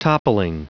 Prononciation du mot toppling en anglais (fichier audio)
Prononciation du mot : toppling